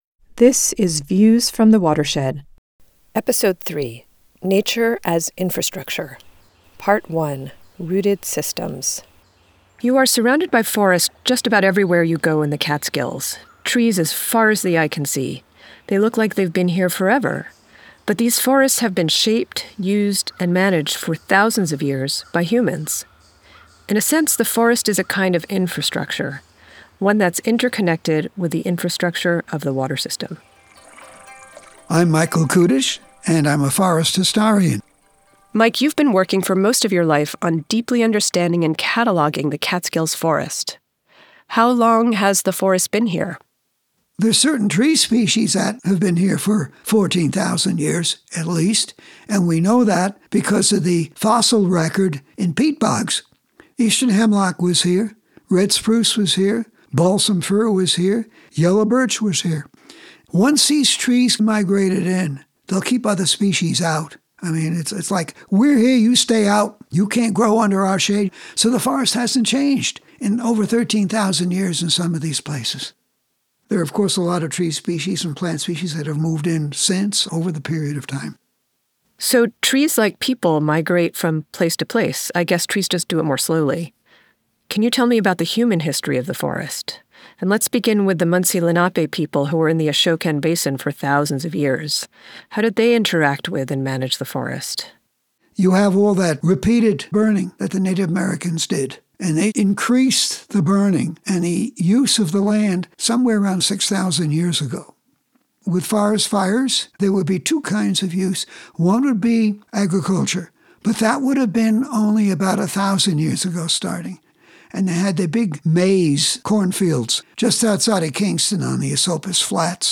It tells the stories of the watershed through firsthand, intimate perspectives from local people (including a historian, a dairy farmer, a former DEP commissioner, a grave restorer, and a forester) on what it means to be a part of the water system.